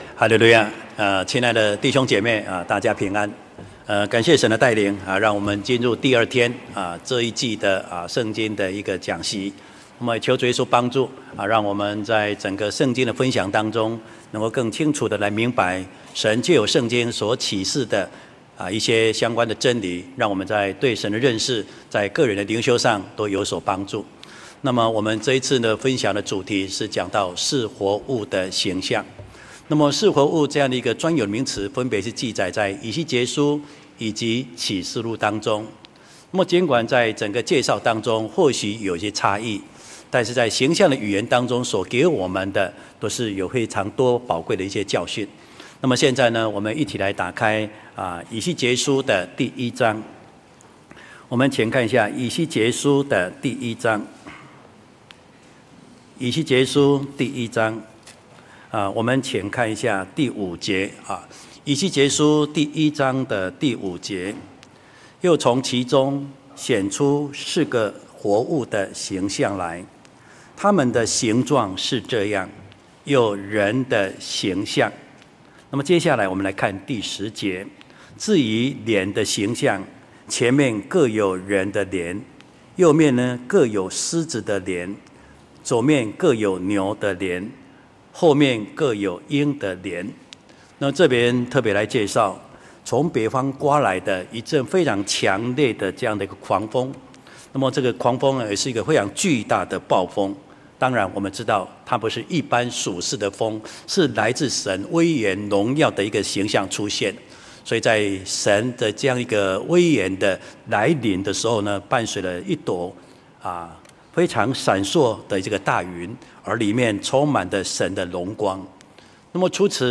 四活物的形象（二）-講道錄音